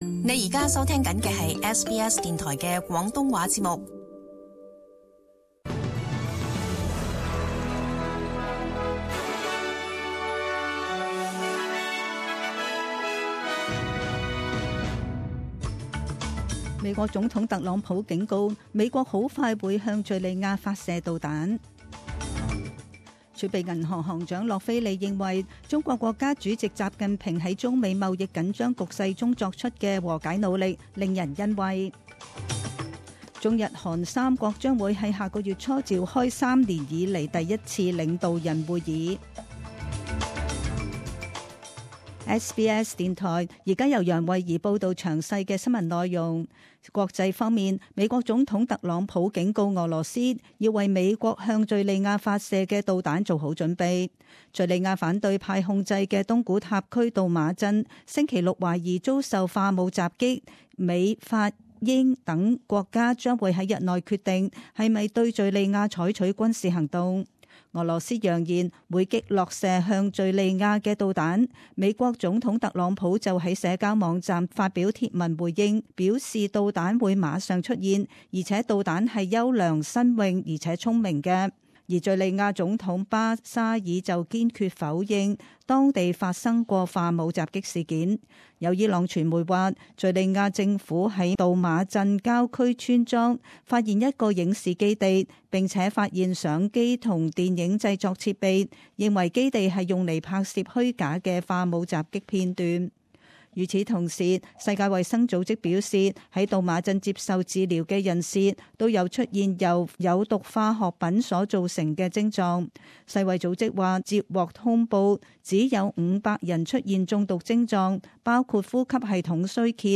SBS中文新闻 （四月十二日）
请收听本台为大家准备的详尽早晨新闻。